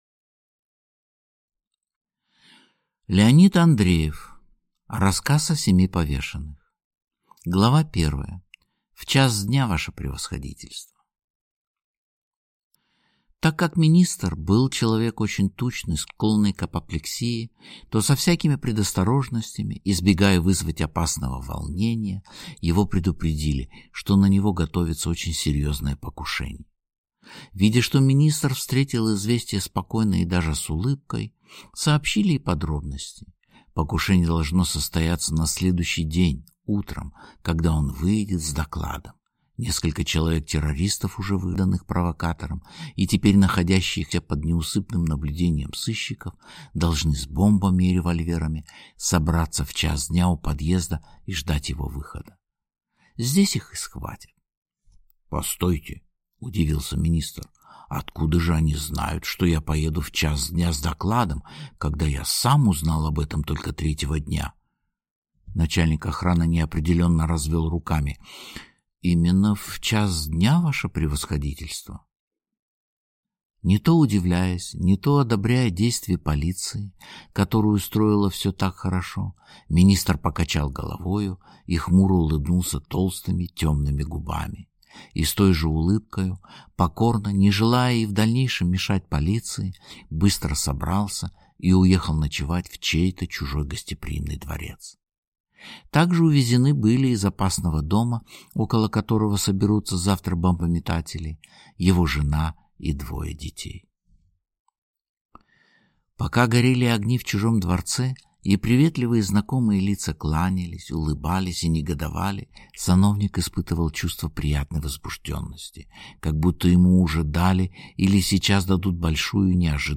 Аудиокнига Рассказ о семи повешенных | Библиотека аудиокниг